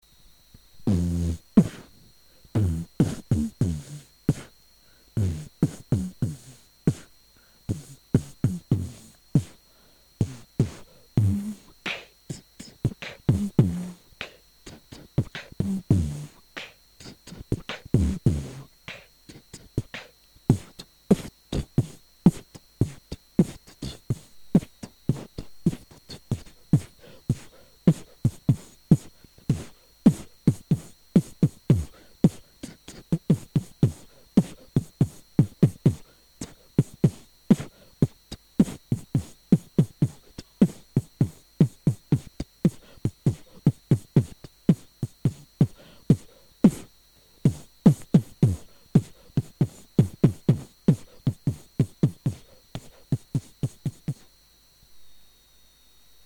Мда... бит не как не позитивный! wacko
Просто запись очень тихая)